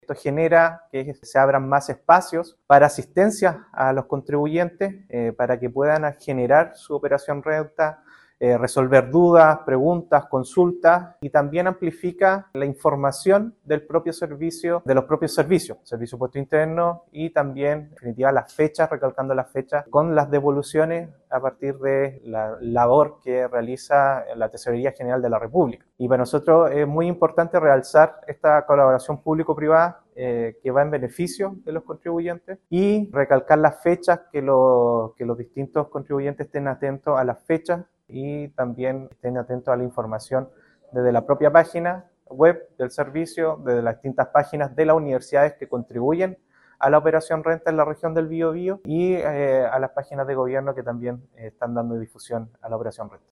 En tanto, el seremi de Hacienda, Sebastián Rivera, enfatizó en el impacto positivo del pago de impuestos y agradeció a todos los actores involucrados en este proceso.